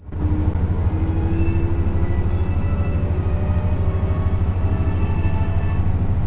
・KTR700・800形車内チャイム
シンプルながら車内チャイムが３曲用意されています。